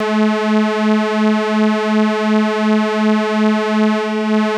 DETUNE.wav